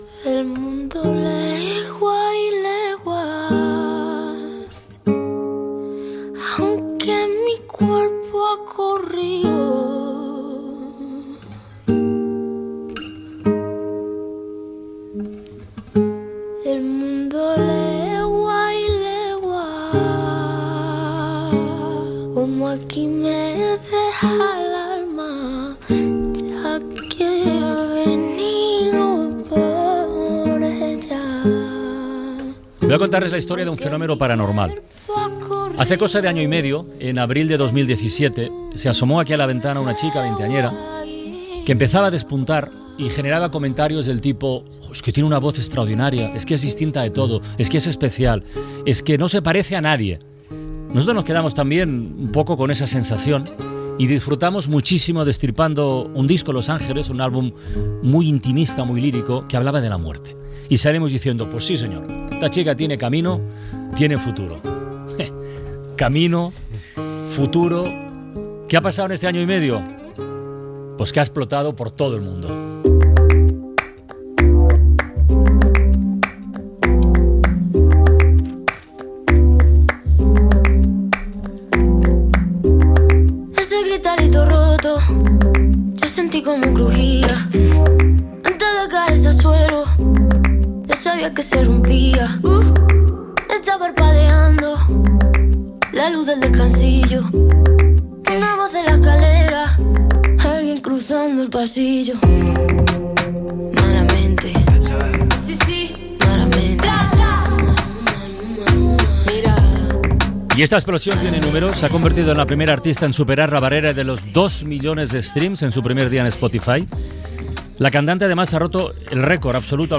Entrevista a la cantant Rosalía (Rosalia Vila) que presenta el disc "Mal querer"